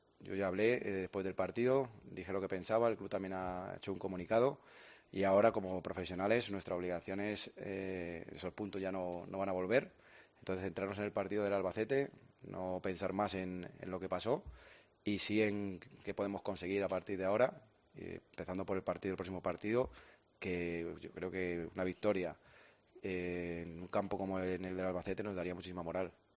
Y lo que sea claro se pite y lo que haya cualquier tipo de duda o sean decisiones grises que sea el árbitro de campo el que toma la decisión final”, dijo en la rueda de prensa previa al choque del viernes ante el Albacete.